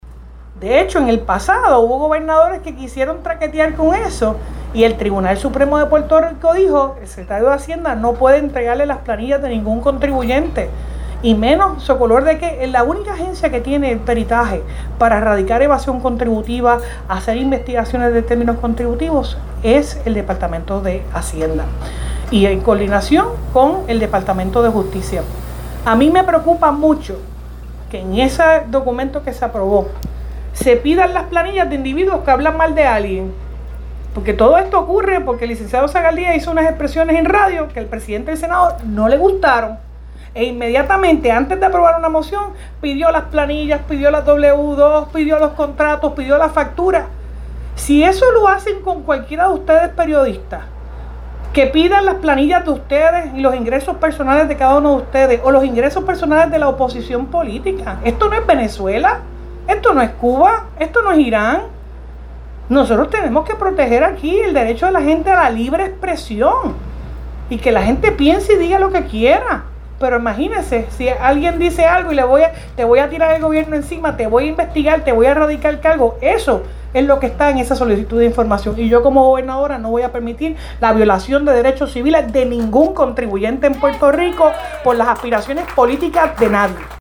La gobernadora Jenniffer González dijo que no permitirá violación de derechos civiles contra ningún contribuyente.